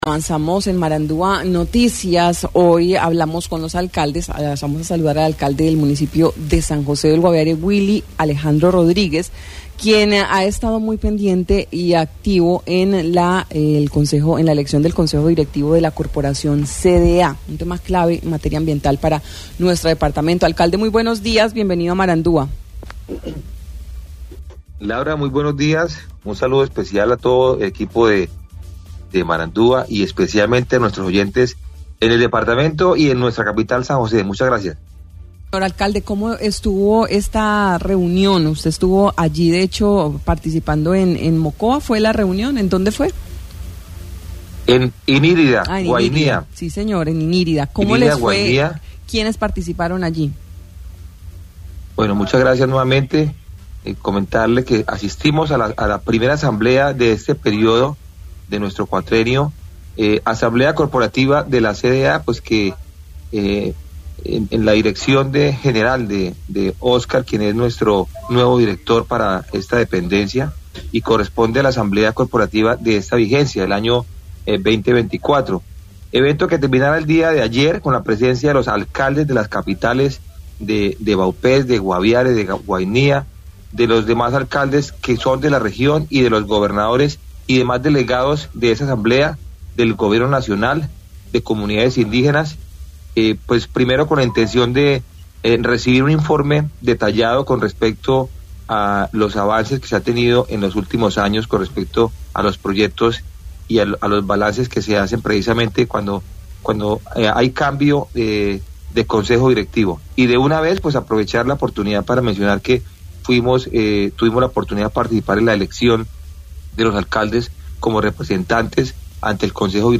Según lo informado por el alcalde de San José del Guaviare, Willy Alejandro Rodríguez, en Marandua Noticias, se llevó a cabo la elección del Consejo Directivo en el marco de la Asamblea Corporativa de la Corporación para el Desarrollo Sostenible del Norte y el Oriente Amazónico (CDA).